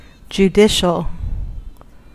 Ääntäminen
US : IPA : [dʒuˈdɪʃəl]